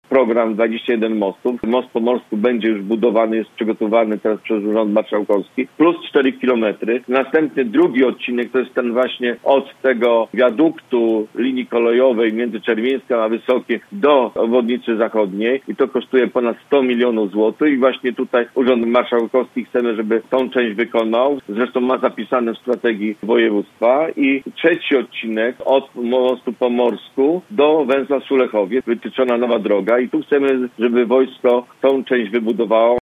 Jerzy Materna był gościem Rozmowy po 9.